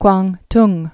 (kwäntng, gwändng)